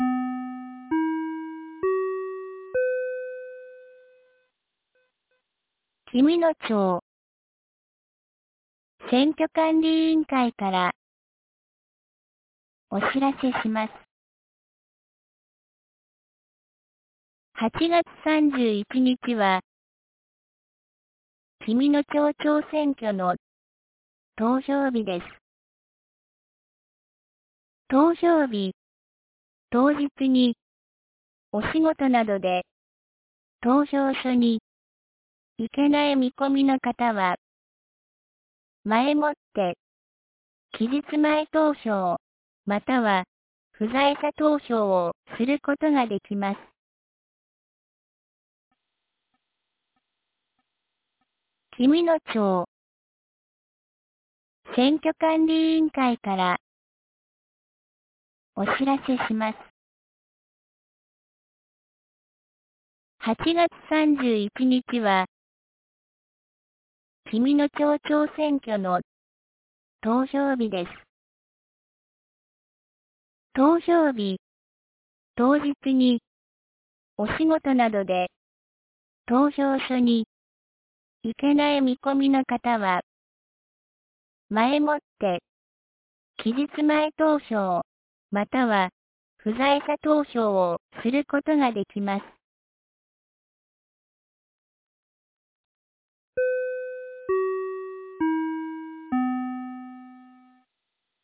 2025年08月29日 12時41分に、紀美野町より全地区へ放送がありました。